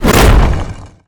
rock_blast_impact_projectile_02.wav